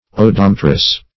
Search Result for " odometrous" : The Collaborative International Dictionary of English v.0.48: Odometrous \O*dom"e*trous\, a. Serving to measure distance on a road.
odometrous.mp3